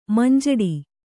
♪ manjaḍi